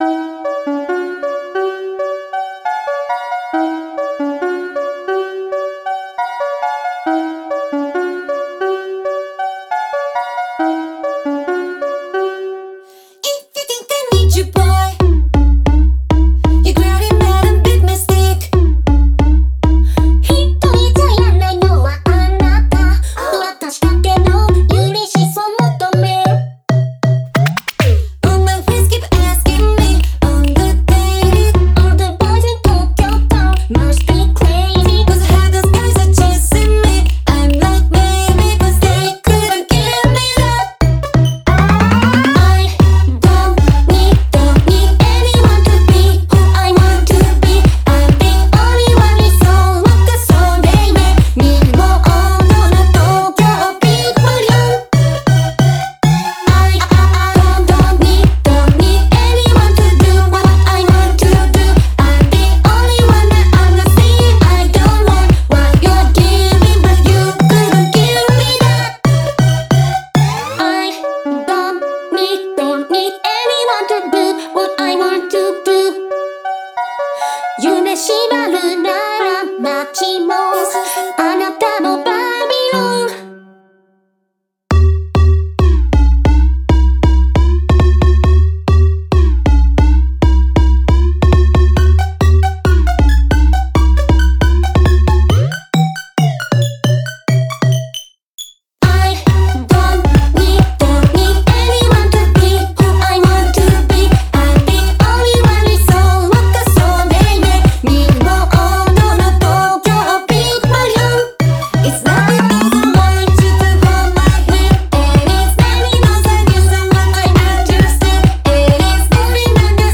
BPM136
Audio QualityMusic Cut
The Vocaloid usage is a bonus in this one.